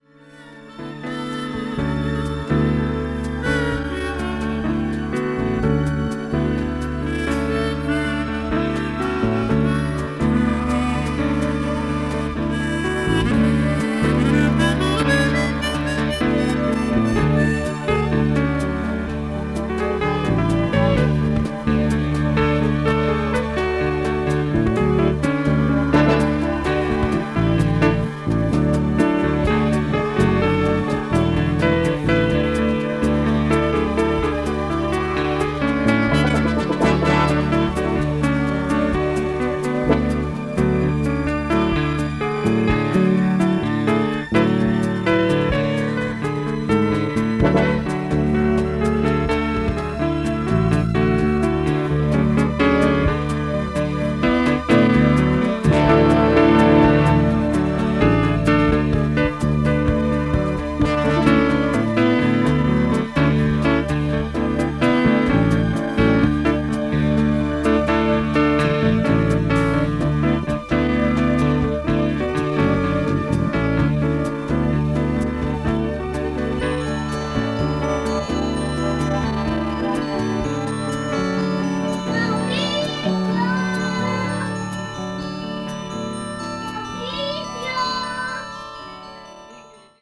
contemporary jazz   crossover   fusion   mellow groove